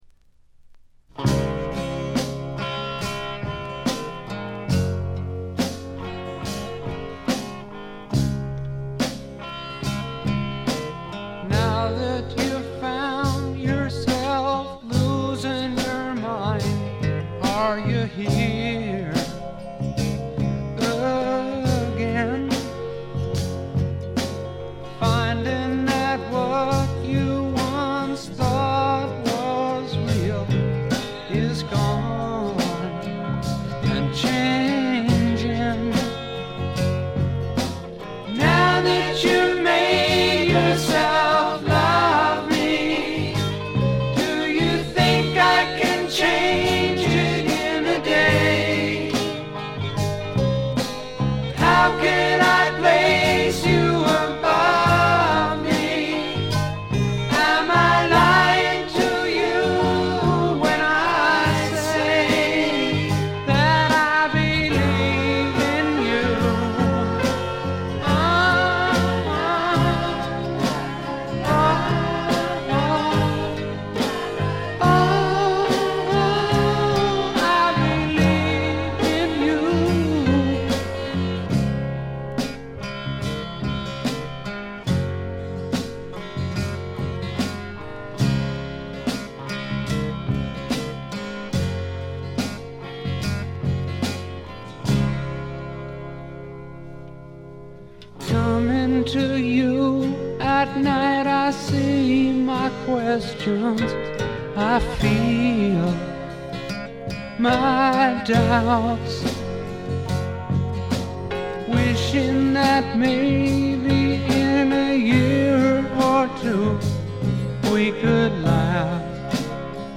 試聴曲は現品からの取り込み音源です。
guitar, piano, vibes, vocal